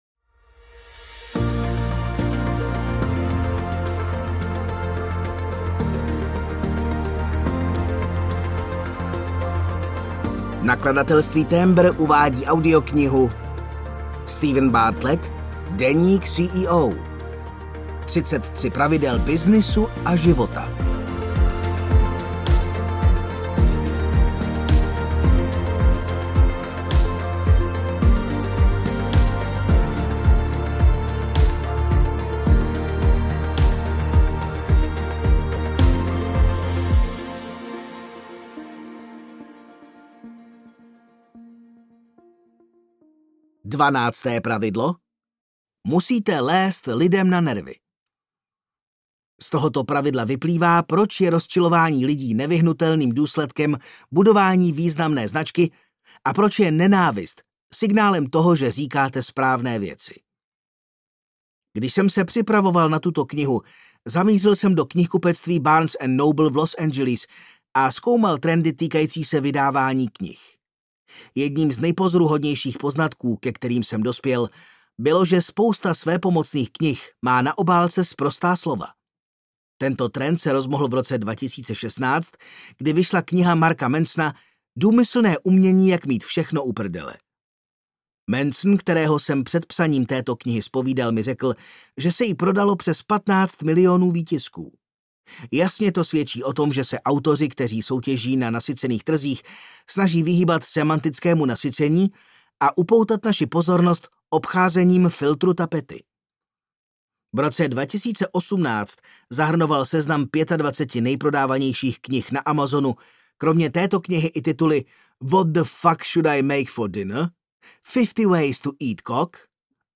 Deník CEO audiokniha
Ukázka z knihy